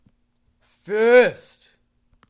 描述：A voice recording.
声道立体声